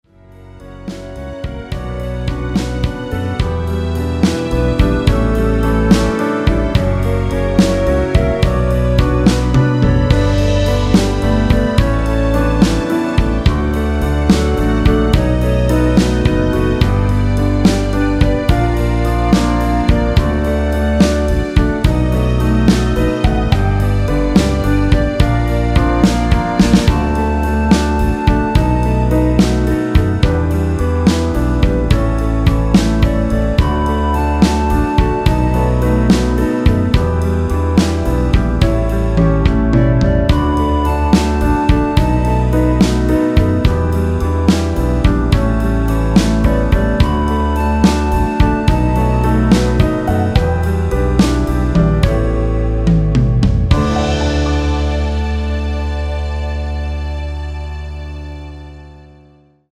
엔딩이 페이드 아웃이라 노래하기 편하게 엔딩을 만들어 놓았습니다.
원키에서(-2)내린 멜로디 포함된 MR입니다.(미리듣기 확인)
멜로디 MR이라고 합니다.
앞부분30초, 뒷부분30초씩 편집해서 올려 드리고 있습니다.
중간에 음이 끈어지고 다시 나오는 이유는